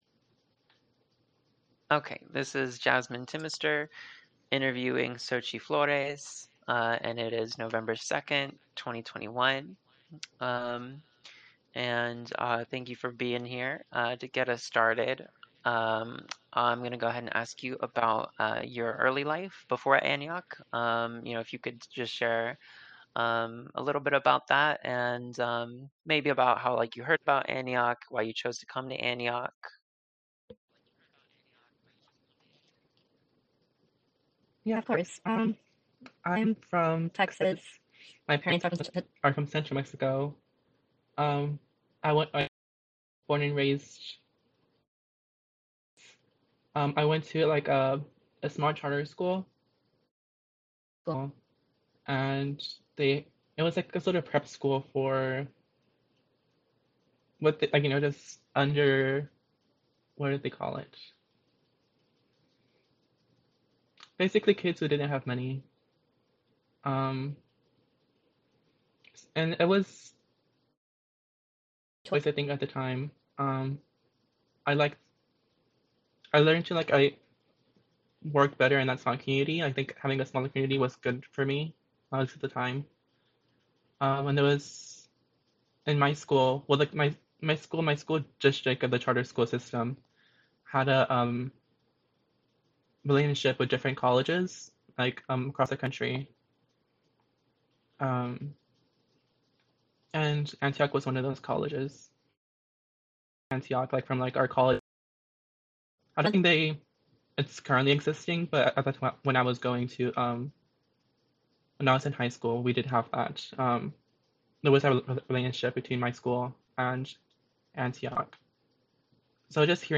Oral History in the Liberal Arts | LGBTQ+ Experiences at Antioch College